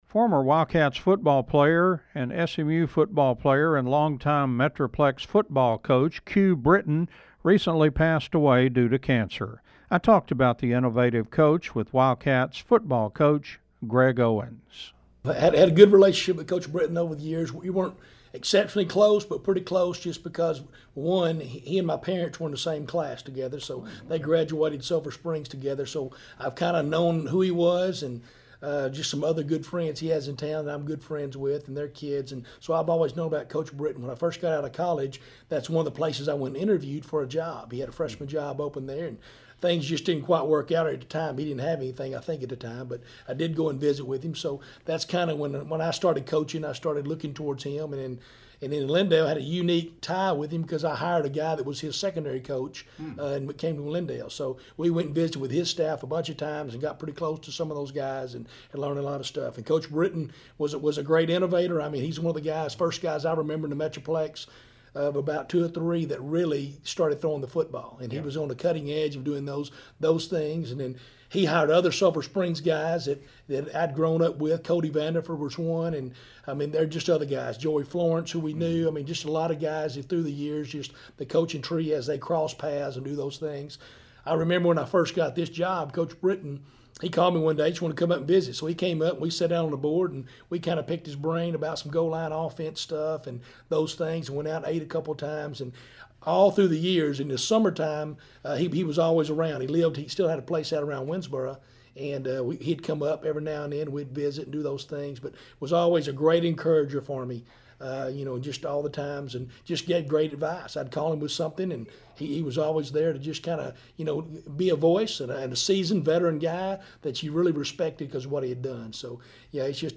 In an interview with KSST Radio